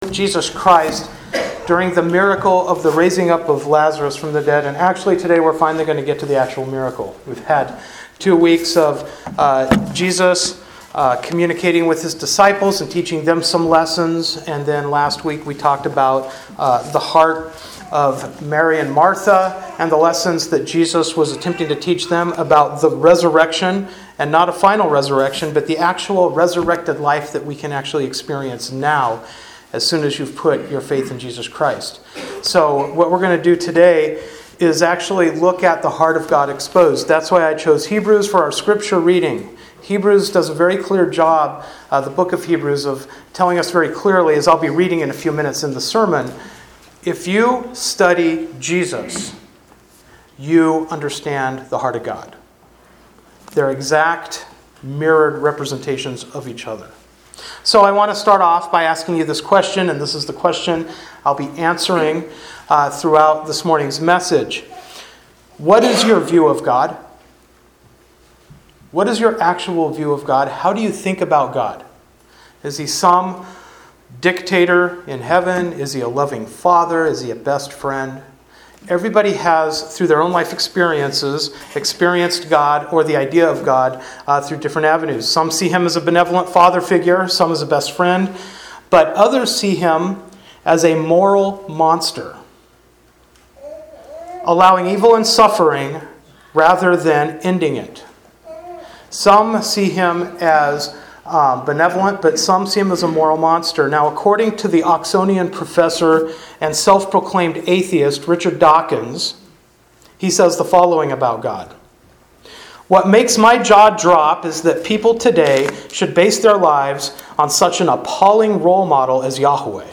John 11:28-44 Service Type: Sunday Morning Worship Bible Text